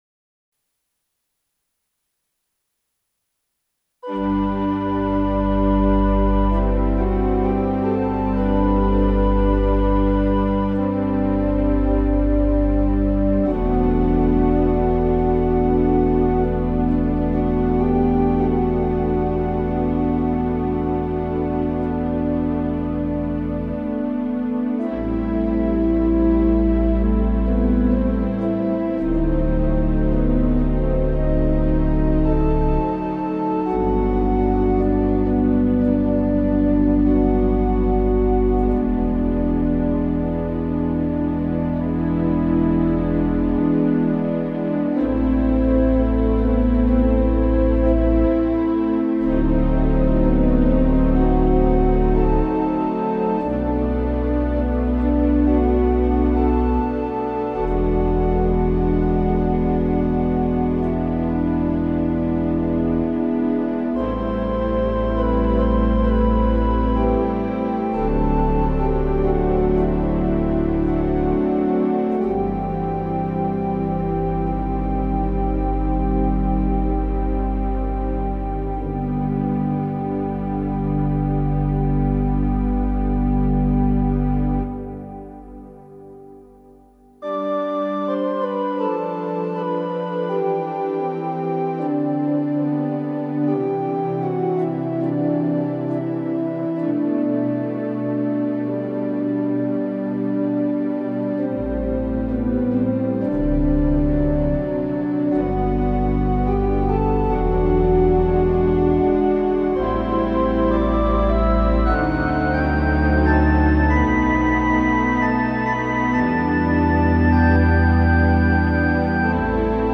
Peaceful works for quiet reflection